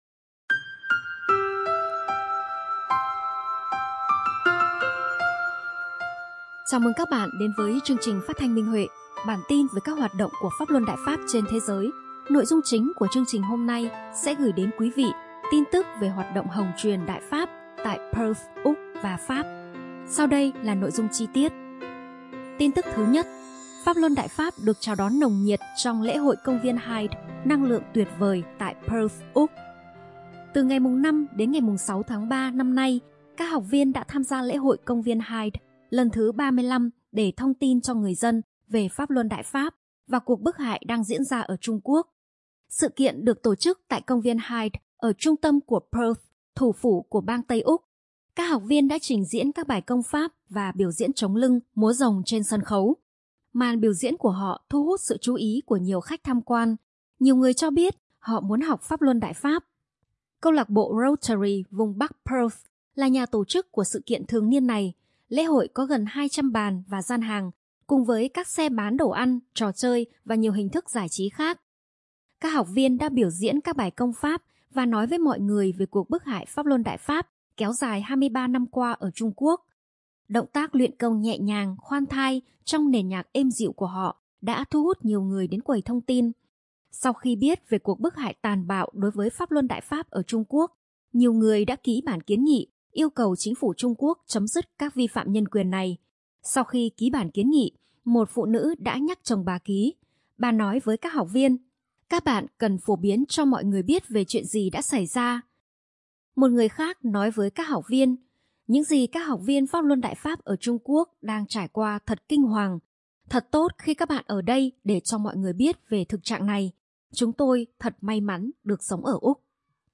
Chào mừng các bạn đến với chương trình phát thanh Minh Huệ.